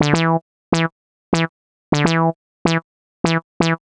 cch_synth_loop_funky_dry_125_D.wav